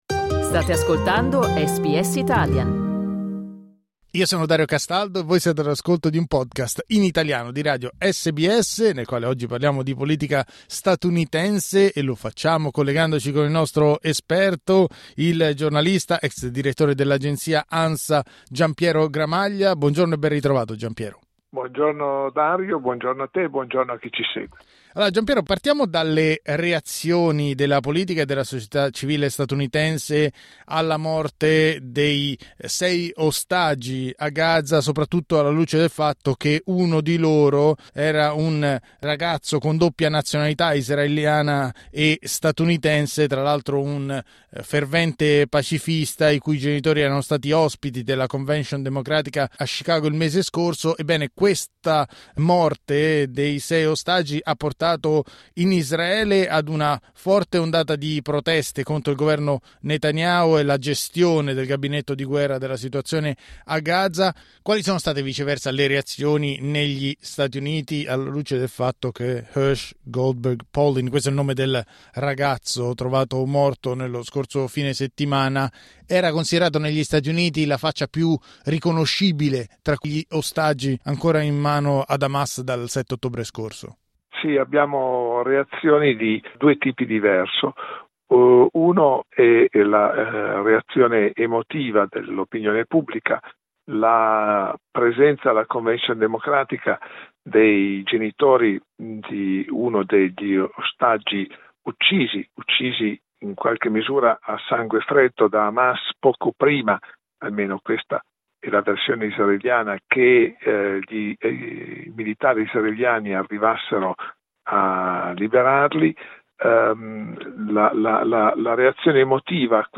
Clicca il tasto play in alto per ascoltare l'analisi del giornalista